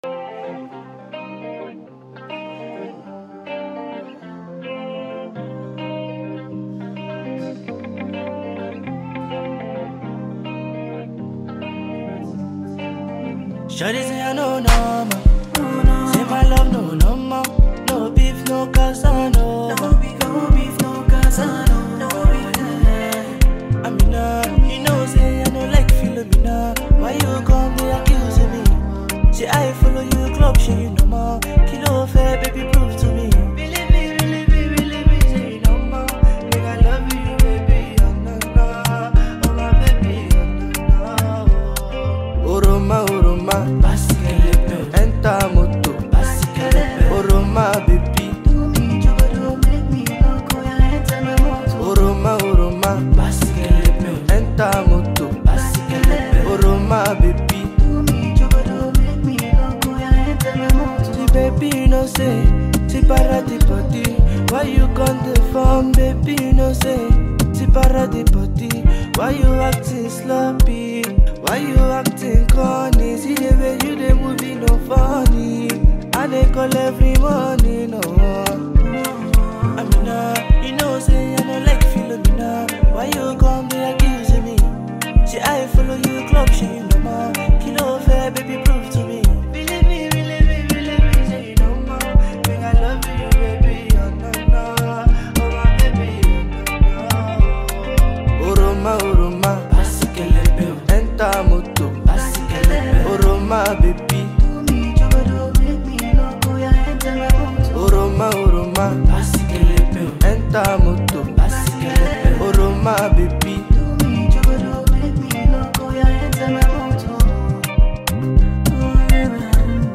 The song is a mix of great beats, meaningful lyrics,